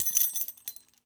foley_keys_belt_metal_jingle_14.wav